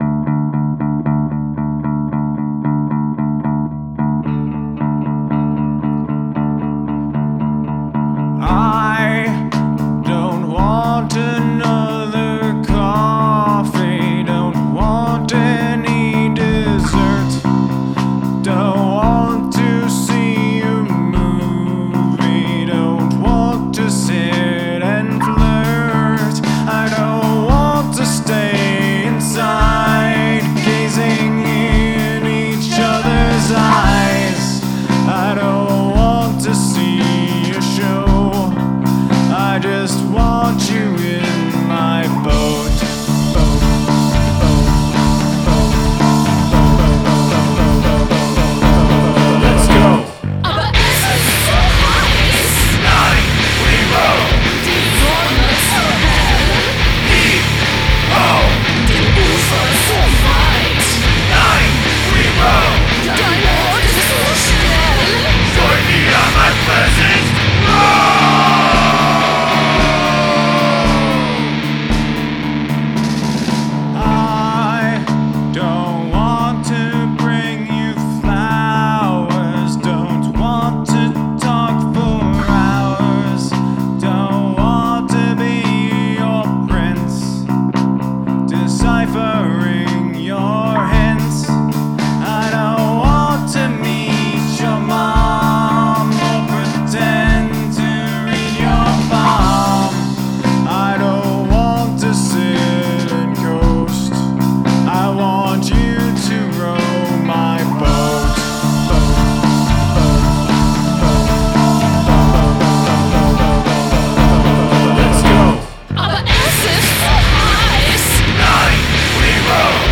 Must include a guest singing or speaking in another language